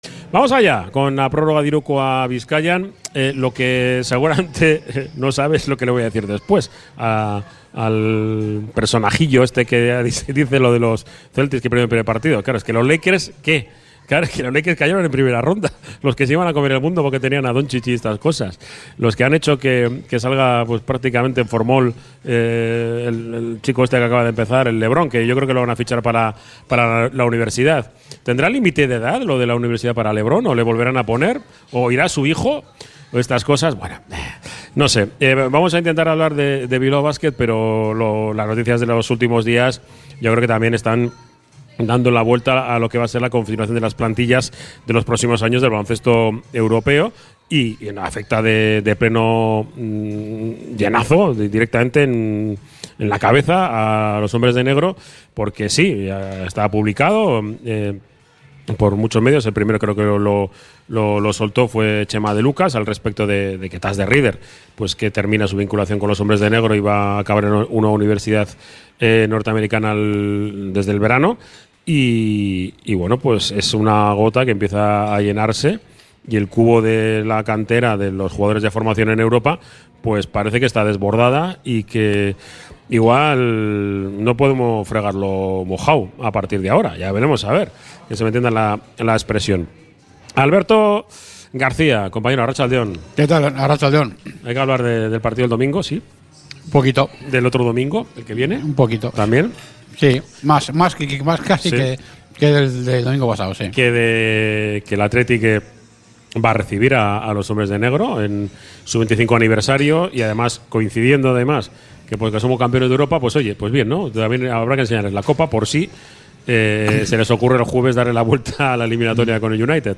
Desde el Bar Izar la Quinta Estrella